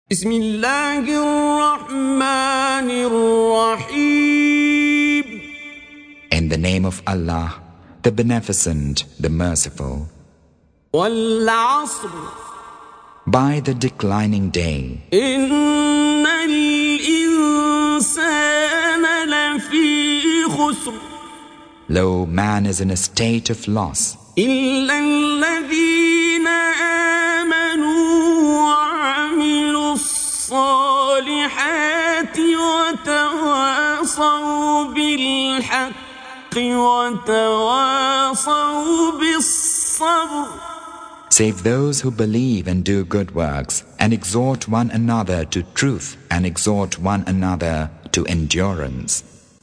Surah Sequence تتابع السورة Download Surah حمّل السورة Reciting Mutarjamah Translation Audio for 103. Surah Al-'Asr سورة العصر N.B *Surah Includes Al-Basmalah Reciters Sequents تتابع التلاوات Reciters Repeats تكرار التلاوات